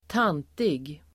Ladda ner uttalet
tantig adjektiv (nedsättande), old-maidish [pejorative]Uttal: [²t'an:tig] Böjningar: tantigt, tantigaDefinition: som anses typiskt för äldre kvinnor